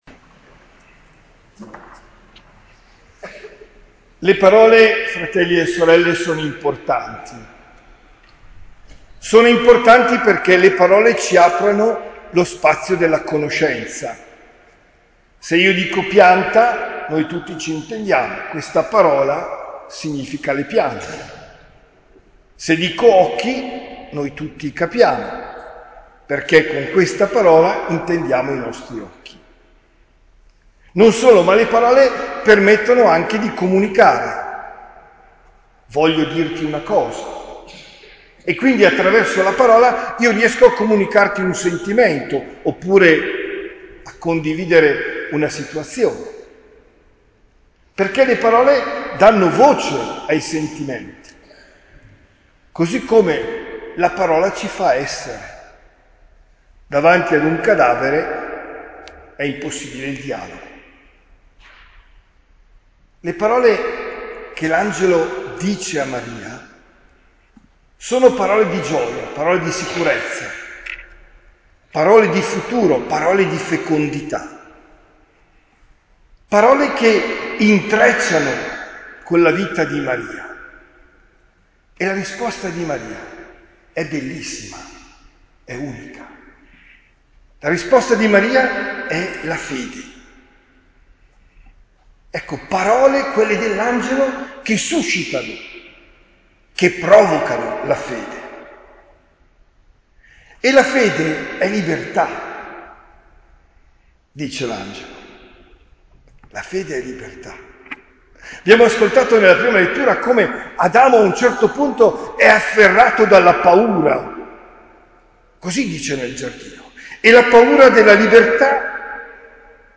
OMELIA DEL 8 DICEMBRE 2022